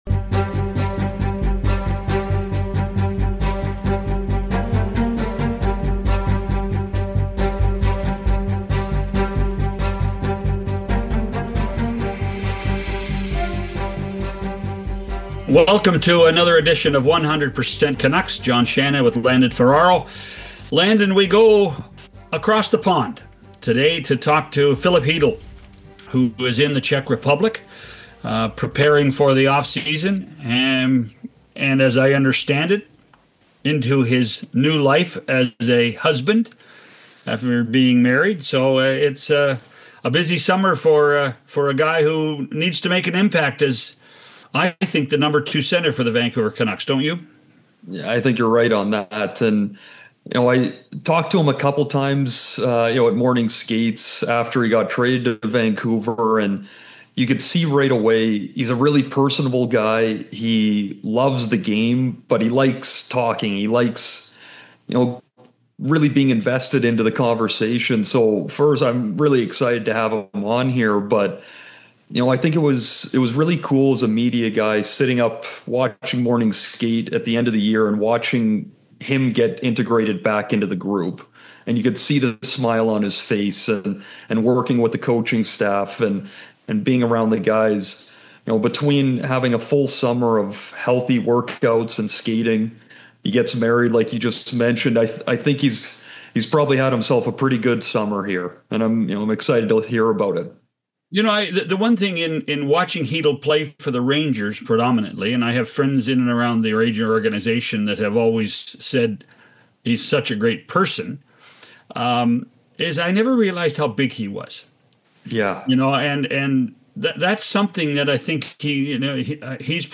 Calling in from the Czech Republic, Filip shares what his offseason has looked like — from training in Europe to his recent marriage — before shifting the focus to hockey.